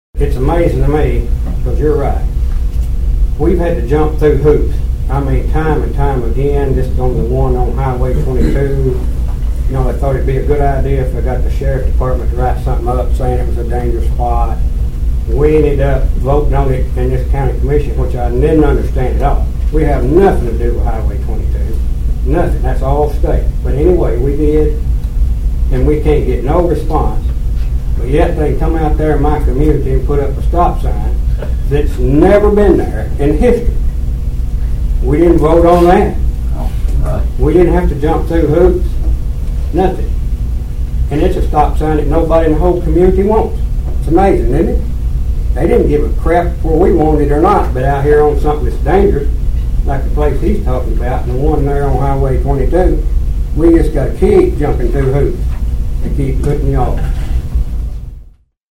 Concern was raised at Monday’s Obion County Commission meeting pertaining to a Department of Transportation response to a dangerous intersection.
Commissioner Kenneth Barnes then explained his issue with new unrequested signage placed in his community.(AUDIO)